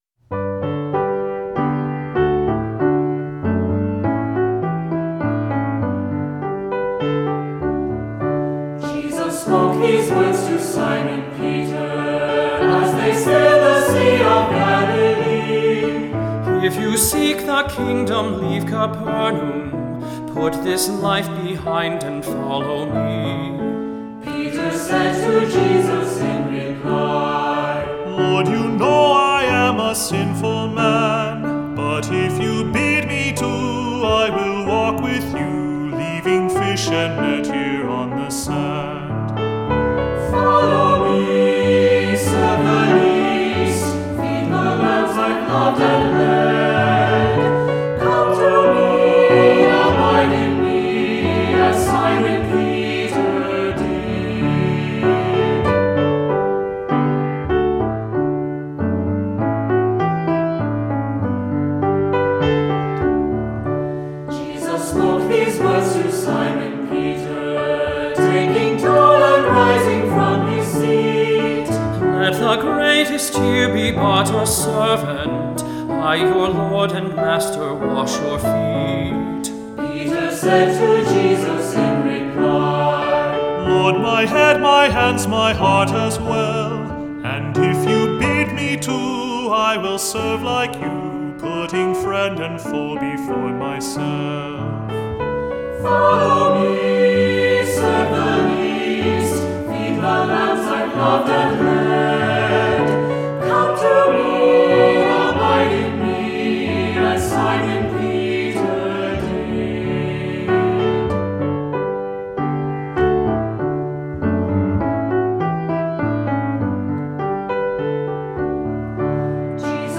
Voicing: 3-part Choir,Unison ChildrenÕs Choir